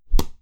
Close Combat Attack Sound 18.wav